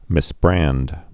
(mĭs-brănd)